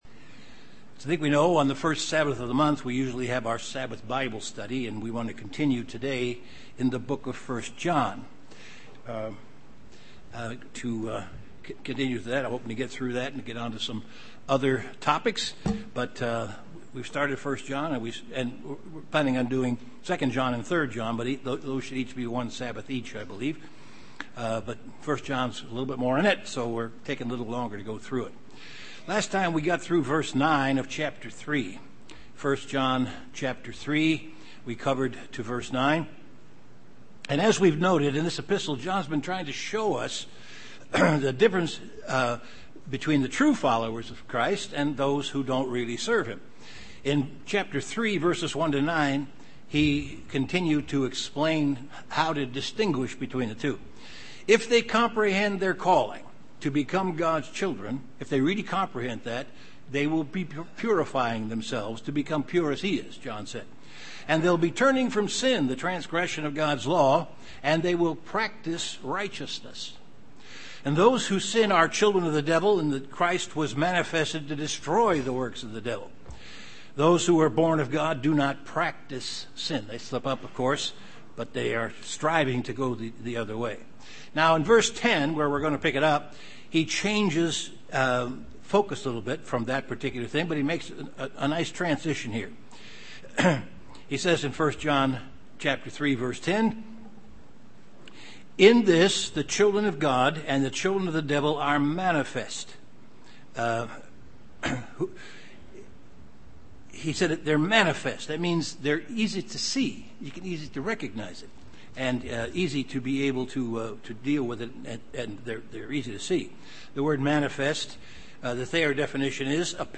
The second in a two part series of an in-depth Bible study on the book of 1 John chapter 3.
Given in Chicago, IL
UCG Sermon Studying the bible?